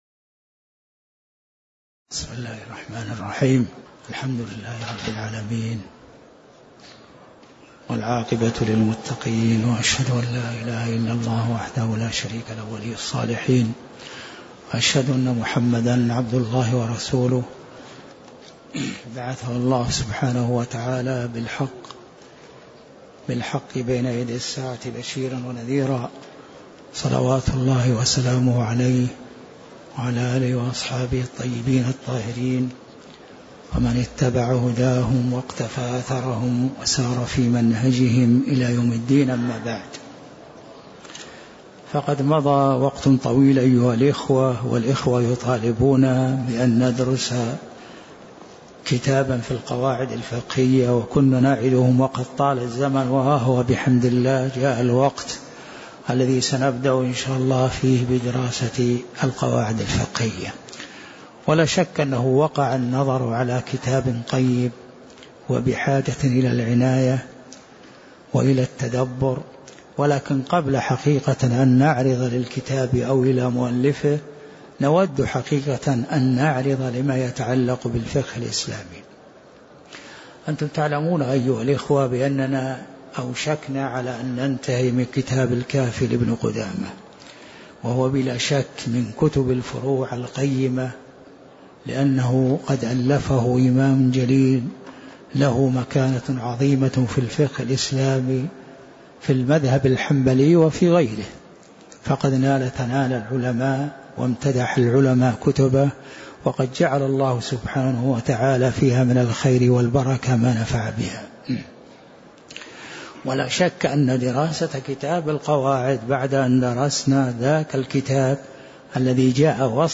تاريخ النشر ٢٠ ربيع الأول ١٤٣١ المكان: المسجد النبوي الشيخ